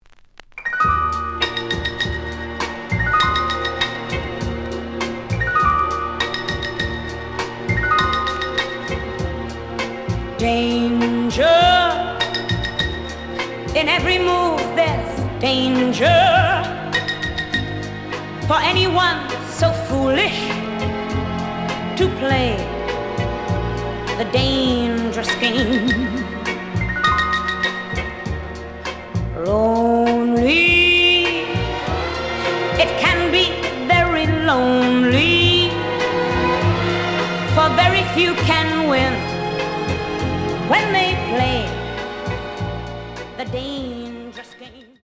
The audio sample is recorded from the actual item.
●Genre: Vocal Jazz